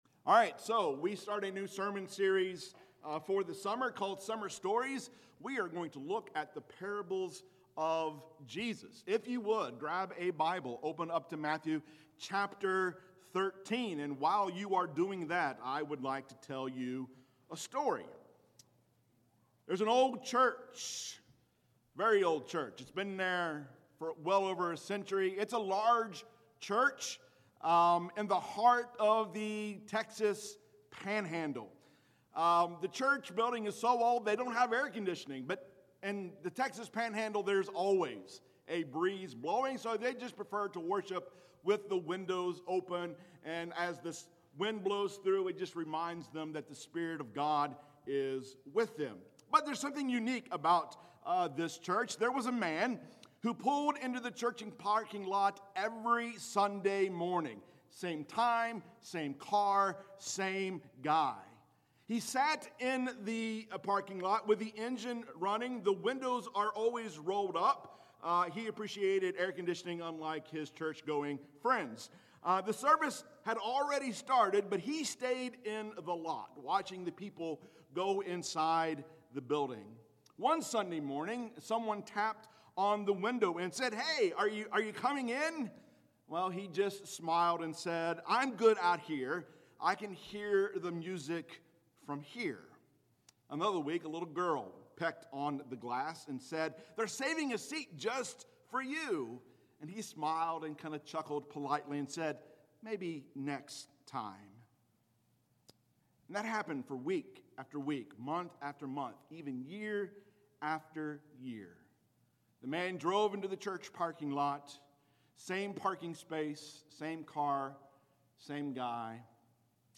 Sermons | Wheeler Road Church of Christ